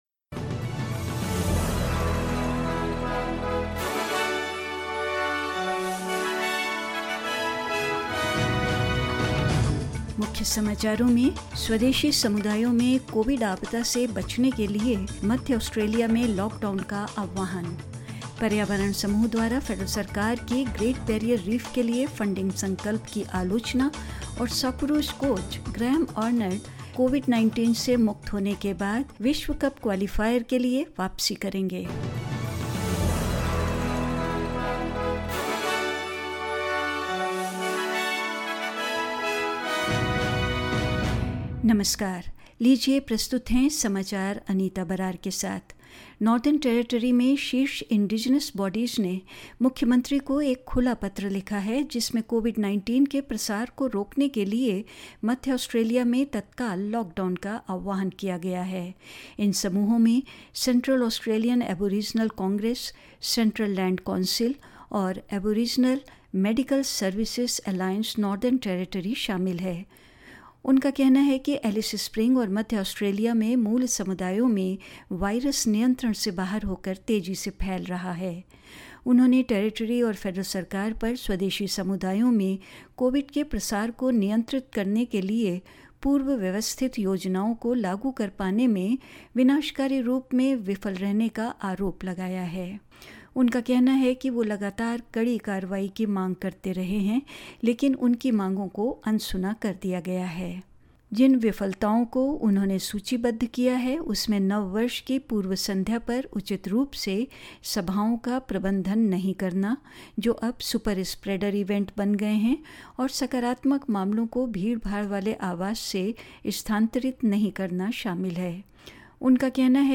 In this latest SBS Hindi bulletin: Calls for lockdown in Central Australia to avoid a COVID catastrophe in Indigenous communities; Environment groups criticise a Federal Government funding pledge for the Great Barrier Reef; and in sport, Socceroos coach Graham Arnold to return for the World Cup qualifier against Oman after being cleared of COVID-19 and more news.